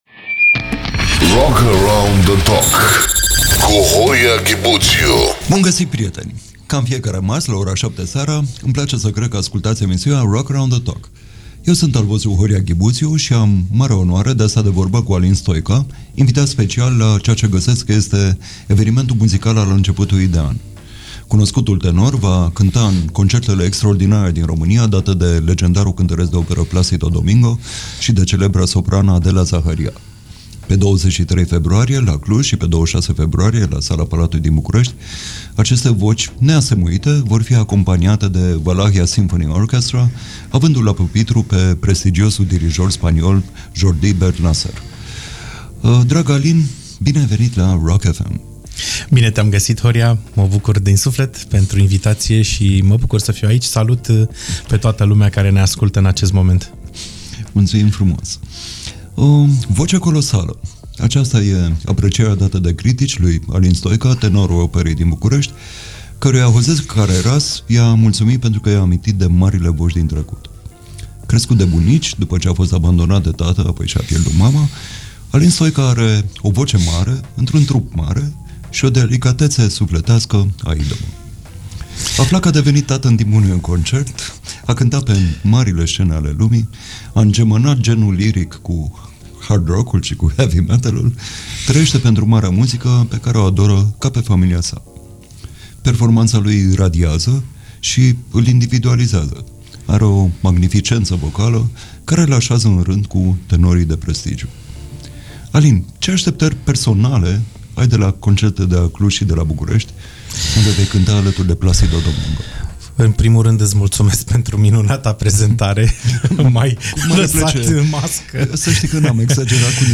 Întrebări meșteșugite și răspunsuri cumpănite, cu câte un invitat distins și un jurnalist destins.